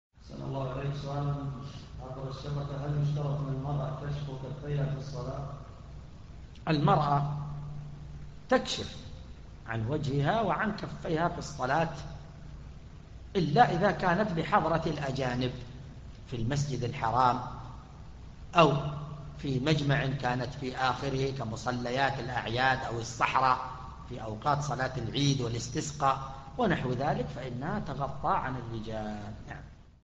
شرح كتاب (بيان فضل علم السلف على علم الخلف (ش :4).
ملف الفتوي الصوتي عدد الملفات المرفوعه : 1